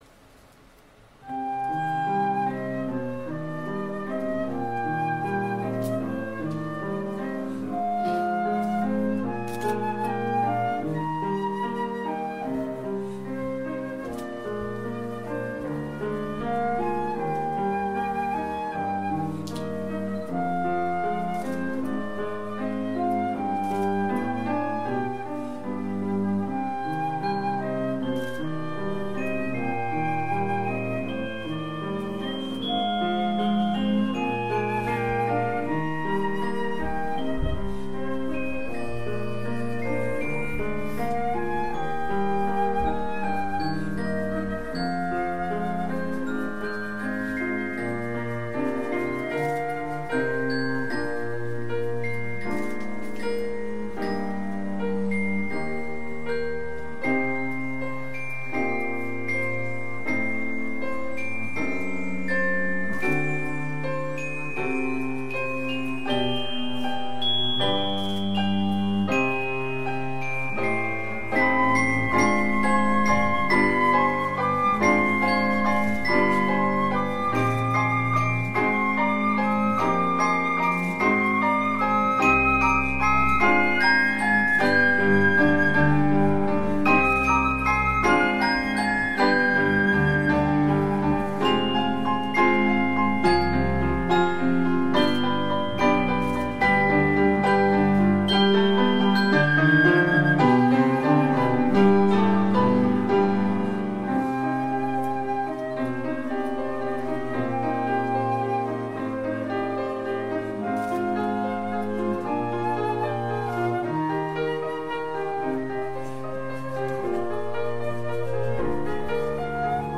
2025年に上演された舞踊と音楽と朗読のコラボレーションによる小泉八雲の『おしどり』の中の１曲です。編成は、フルート、ピアノ、コントラバス、グロッケン。会場録音なので音質はもうひとつです。